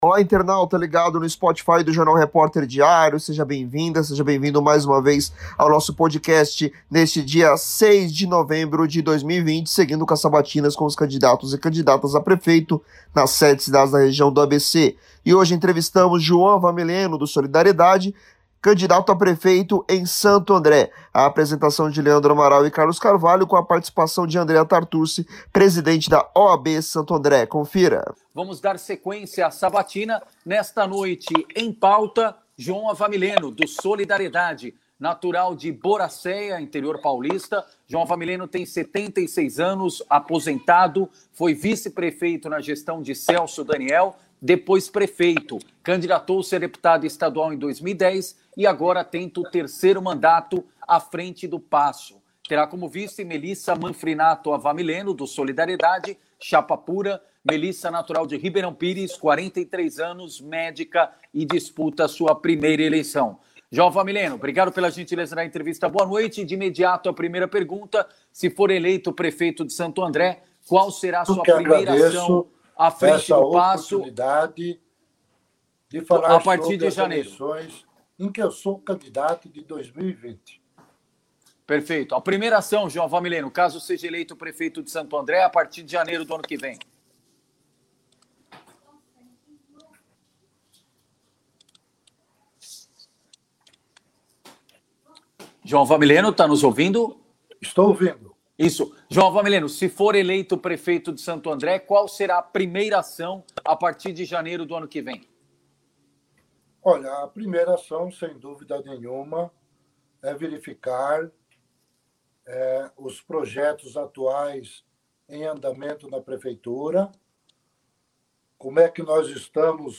O projeto da Linha-18 Bronze por meio de um monotrilho no ABC foi substituído no ano passado por um corredor de ônibus do Bus Rapid Transit – BRT. Mas, em sabatina promovida pelo RD em parceria com a Rádio ABC e a OAB (Ordem dos Advogados do Brasil) nesta sexta-feira (6/11), o candidato a prefeito em Santo André, João Avamileno (SD) afirmou que o projeto deverá ser resgatado caso seja eleito na cidade.